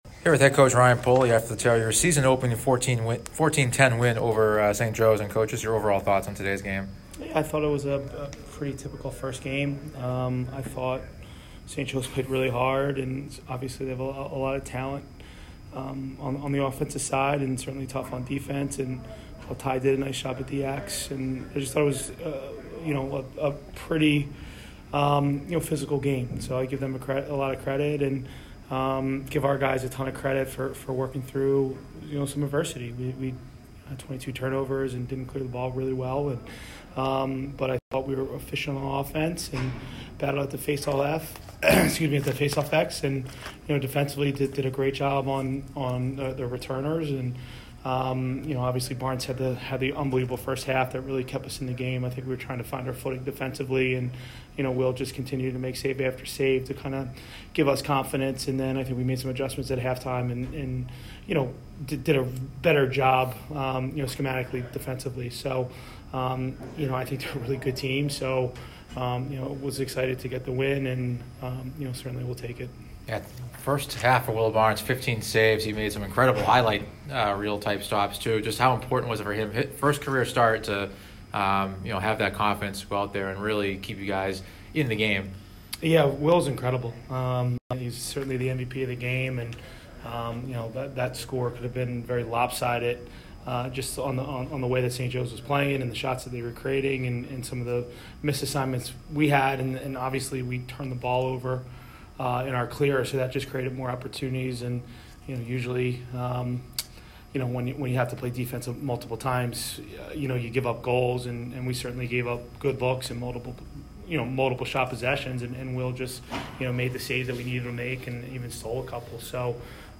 Saint Joseph's Postgame Interview